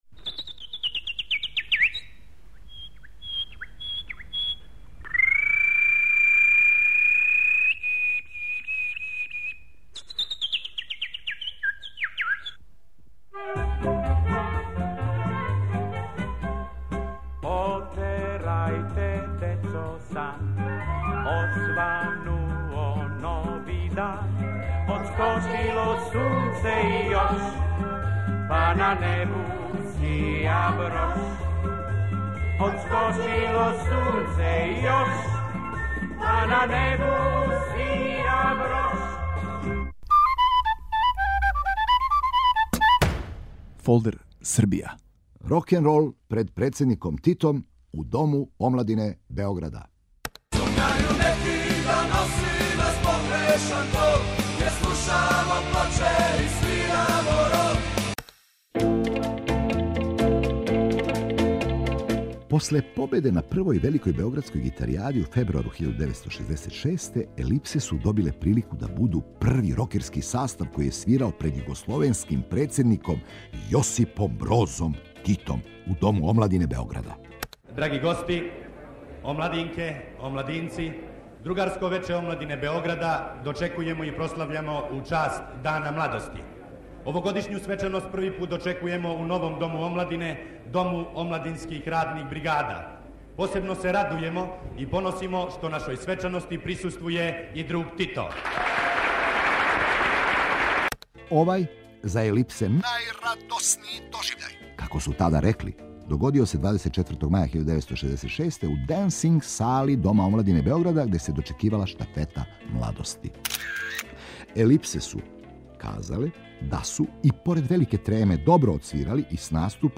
У серијалу ФОЛДЕР СРБИЈА: "Рокенрол пред председником Титом", текст: Александар Раковић. Ваш водич кроз Србију: глумац Бранимир Брстина.